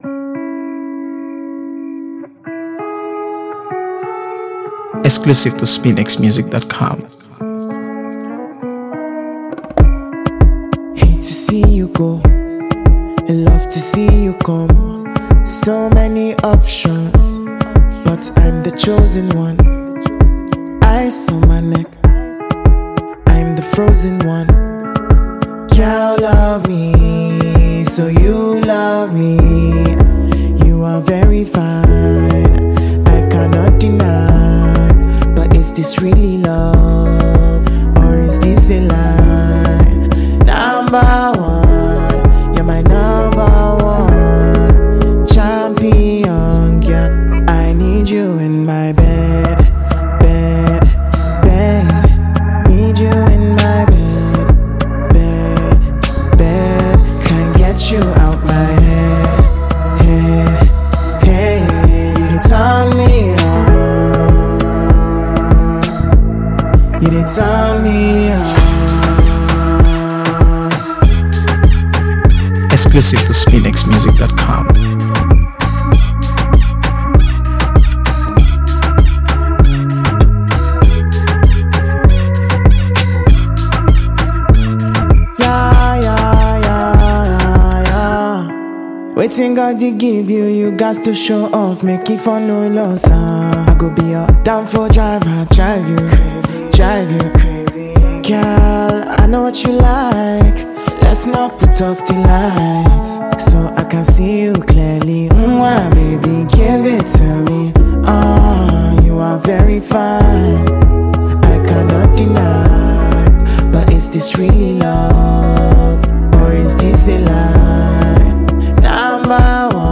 AfroBeats | AfroBeats songs
Sultry
With its intimate lyrics and lush instrumentation
and his velvety vocals weave a sensual narrative.